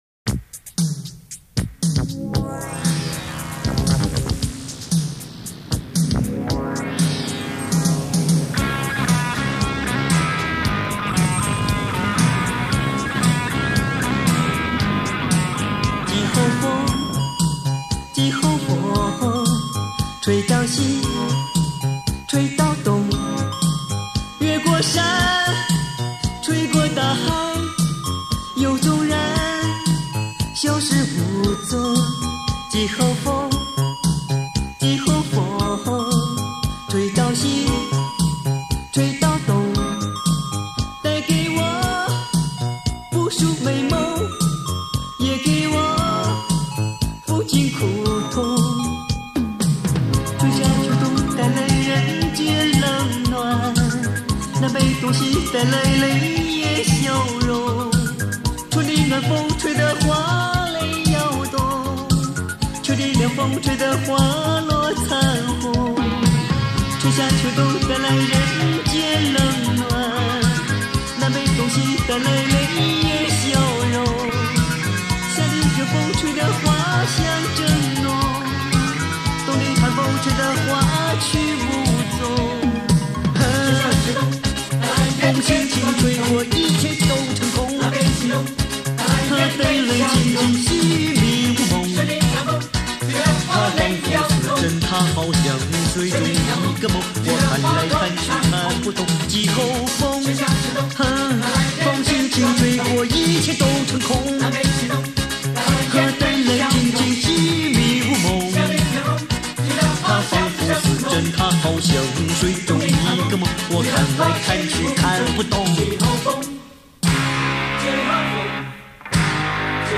电子音效和动感节奏的配器在当时国内无疑极具先锋和前卫意义